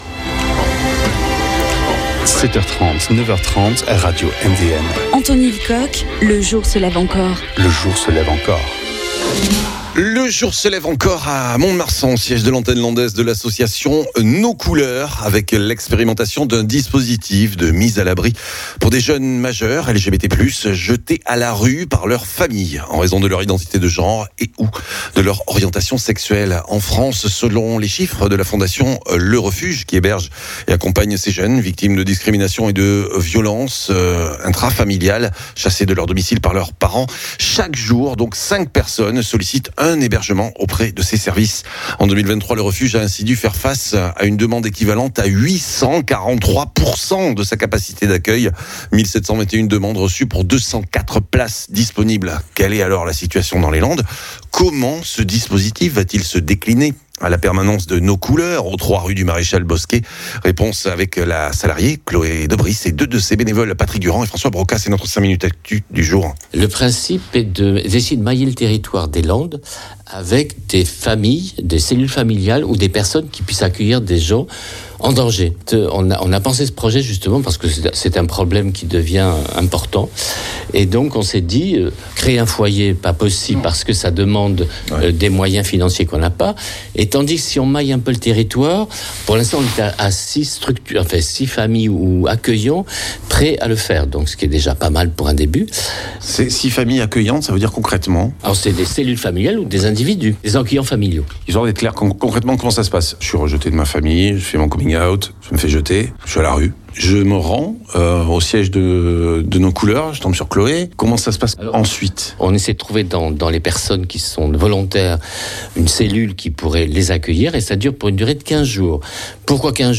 Explications à la permanence de l’association rue Bosquet